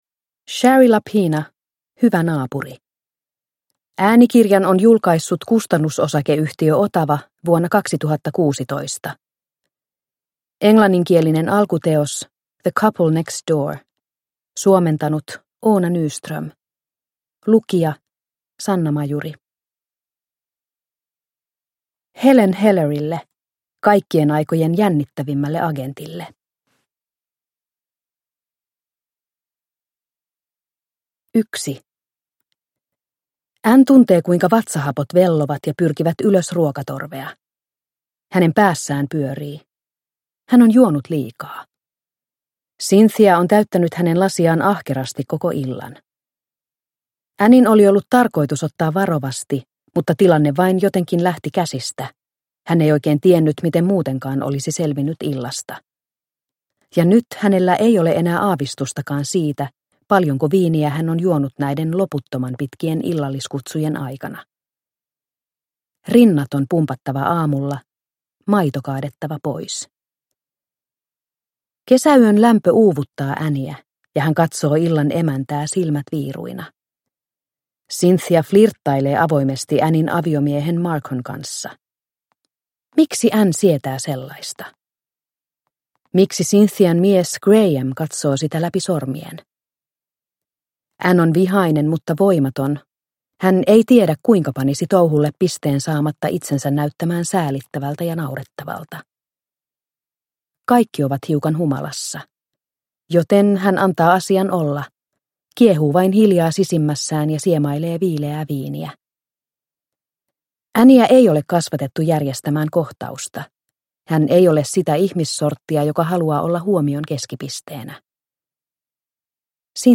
Hyvä naapuri – Ljudbok – Laddas ner